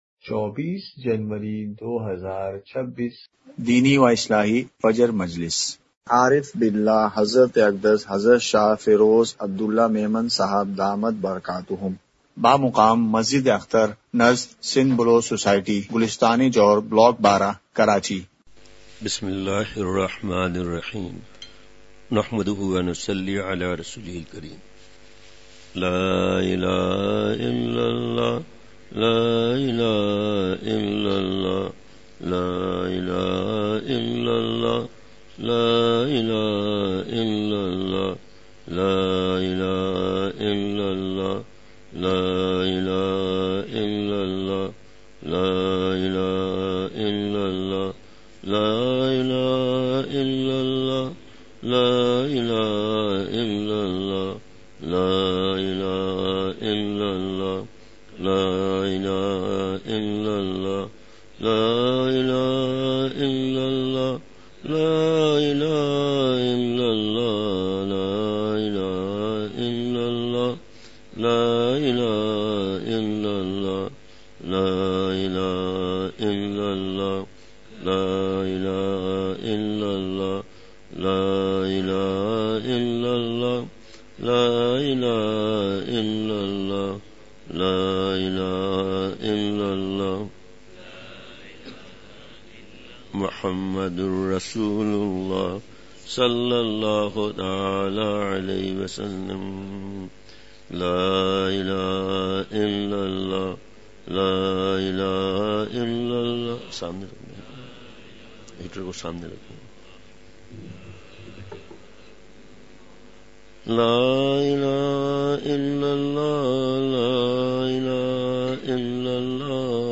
اصلاحی مجلس
*مقام:مسجد اختر نزد سندھ بلوچ سوسائٹی گلستانِ جوہر کراچی*